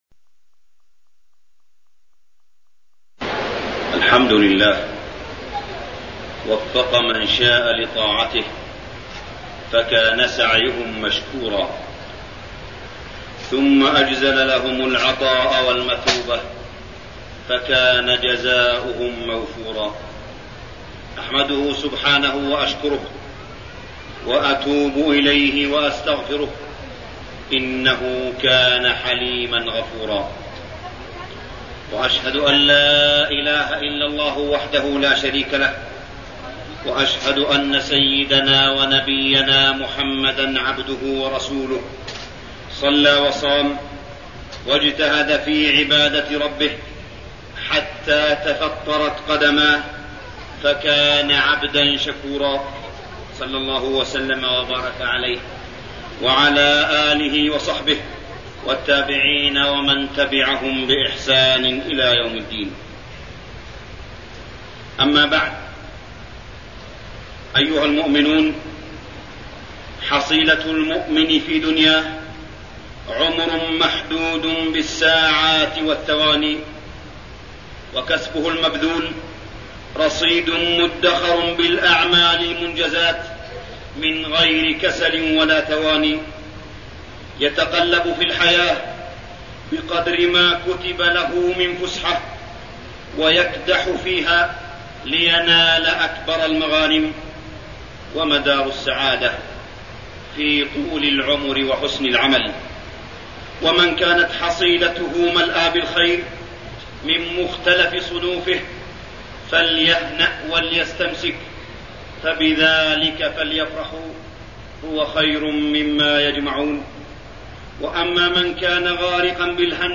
تاريخ النشر ٢٩ رمضان ١٤٠٩ هـ المكان: المسجد الحرام الشيخ: معالي الشيخ أ.د. صالح بن عبدالله بن حميد معالي الشيخ أ.د. صالح بن عبدالله بن حميد ختام شهر رمضان وزكاة الفطر